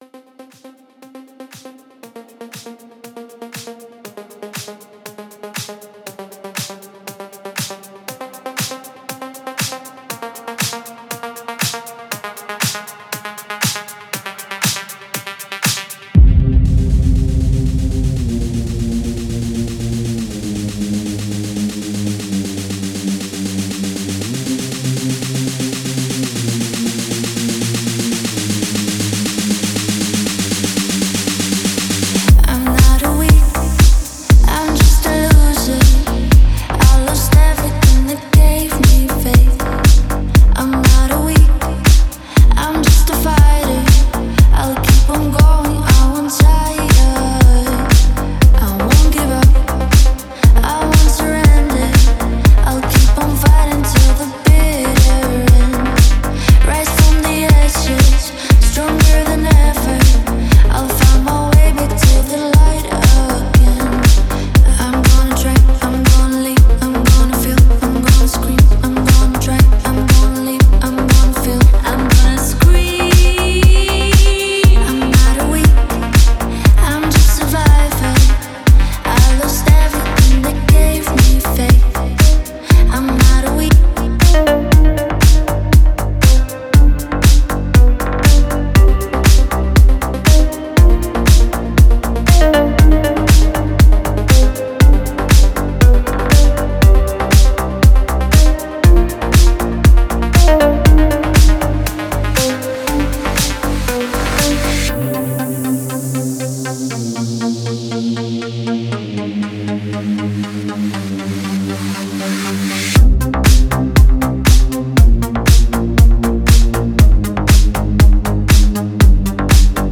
Дип Хаус треки 2025
Deep House музыка